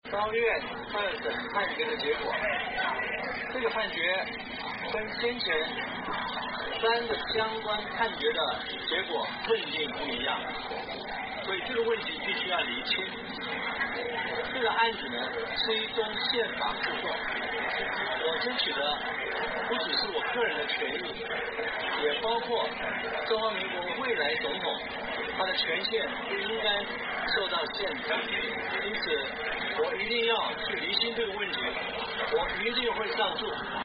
马英九记者会对高院判决的反应(原声)